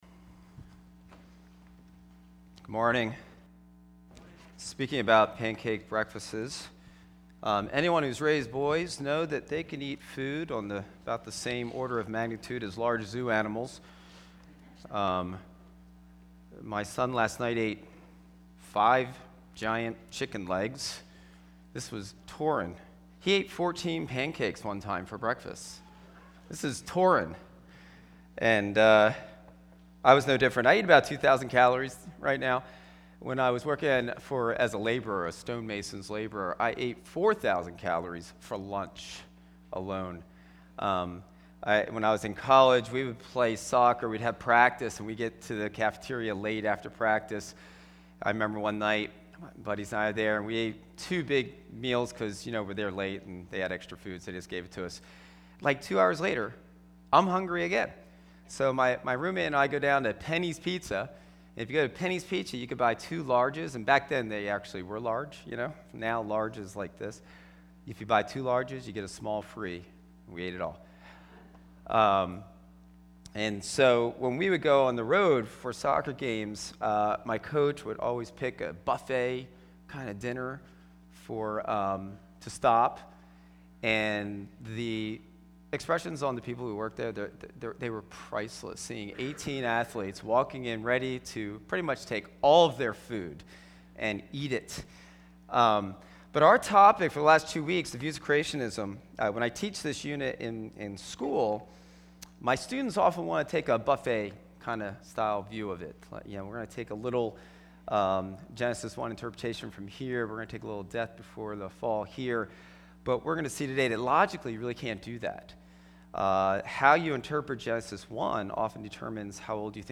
A special mini-series that was recorded on Saturdays. Get ready to tackle some of the most debated verses of the Bible!